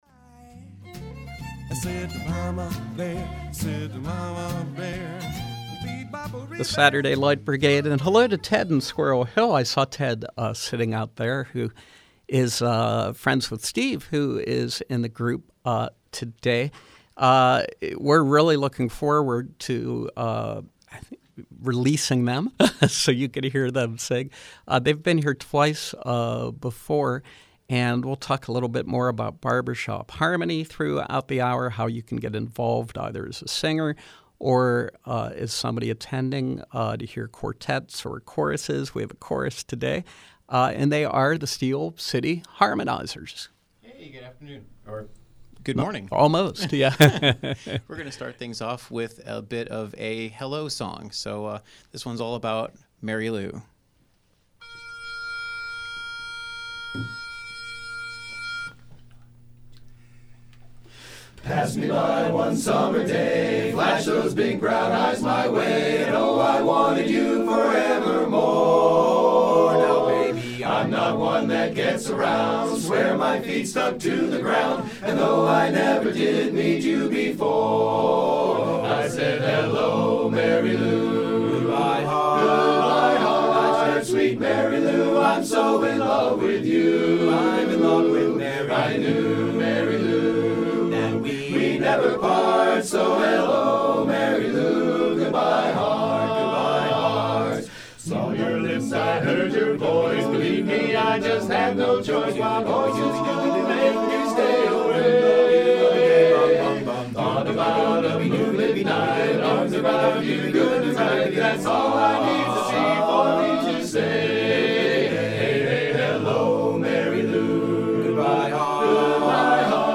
Live folk music